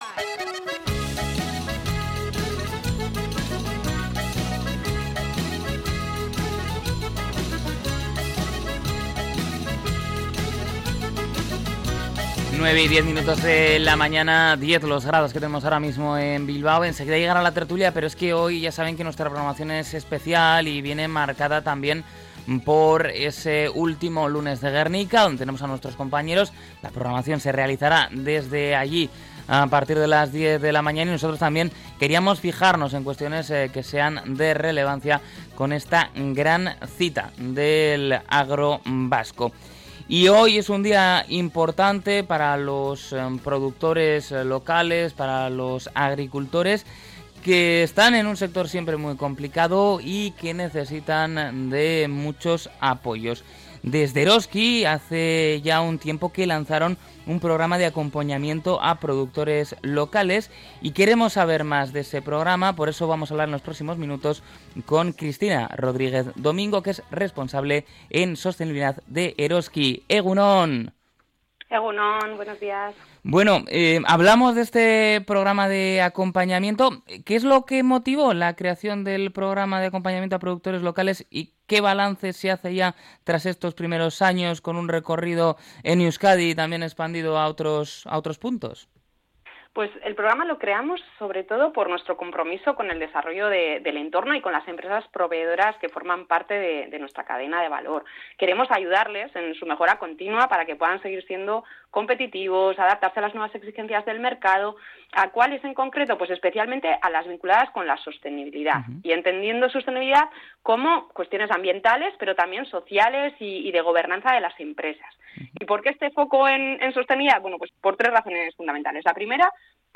Entrevista-Eroski-productores-locales.mp3